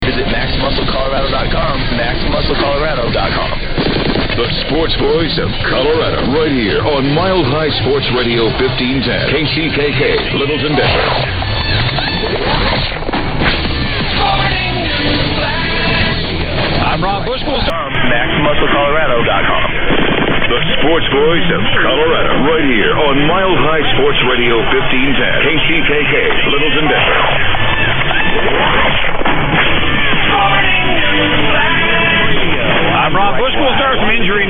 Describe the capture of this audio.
But here is a taste of how KCKK Denver came in at 0400.